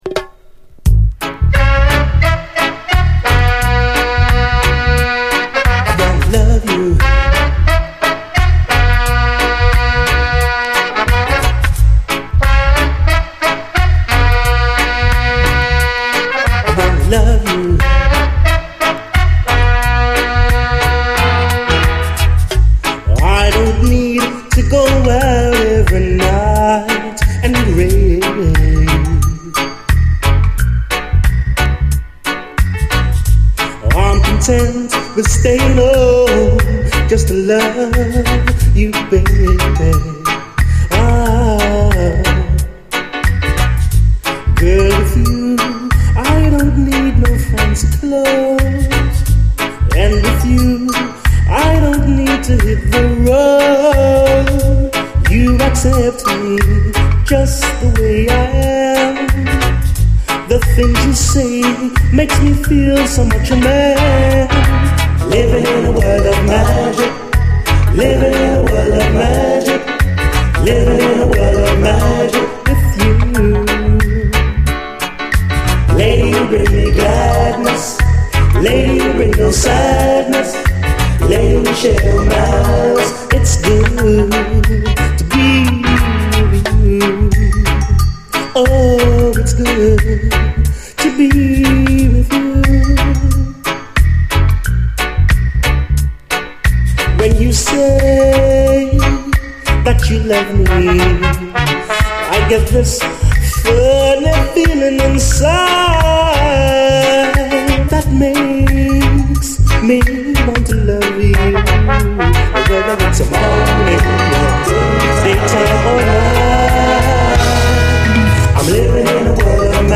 REGGAE
UKラヴァーズ界随一のコーラス！トロけるような美メロ＆美コーラスで酔わせる、文句なしのグレイト・チューン！
見た目盤スレ結構ありますが実際は概ね綺麗に聴けます。
トロけるような美メロ＆美コーラスで酔わせる、文句なしのグレイトUKラヴァーズ！